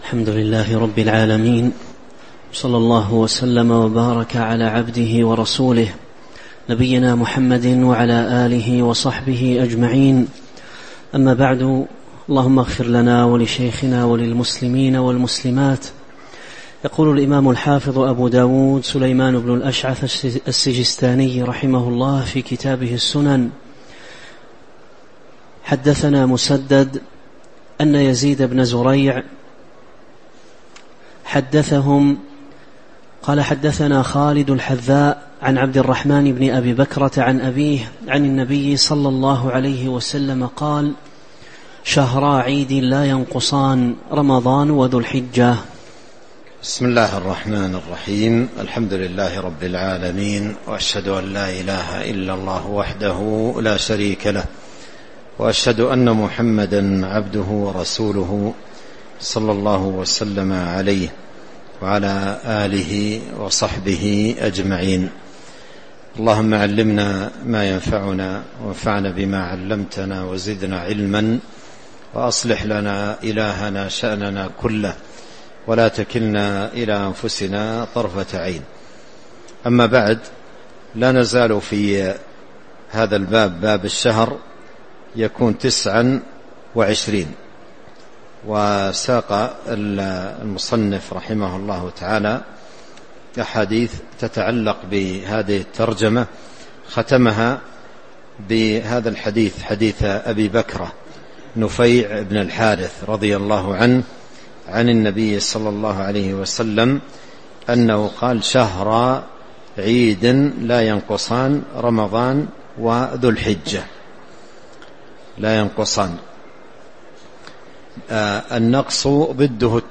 تاريخ النشر ٣ رمضان ١٤٤٦ هـ المكان: المسجد النبوي الشيخ